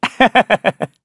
Kibera-Vox_Happy1_kr.wav